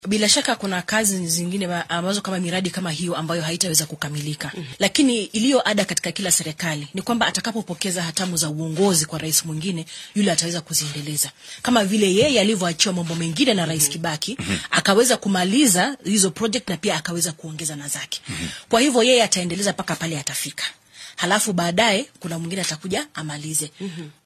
Afhayeenka madaxtooyada dalka looga arrimiyo ee State House ,Kanze Dena oo maanta wareysi gaar ah siisay idaacadda Inooro ayaa sheegtay in madaxweyne Uhuru Kenyatta ay ka go’an tahay xaqiijinta sidii ay wadanka uga dhici lahayd doorasho xor iyo xalaal ah. Waxay hoosta ka xarriiqday in hoggaamiyaha wadanka uu mudnaanta koowaad siinaya in codeynta 9-ka bisha Siddeedaad ee sanadkan ay ku qasoonto jawi nabad ah.